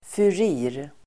Ladda ner uttalet
Uttal: [fur'i:r]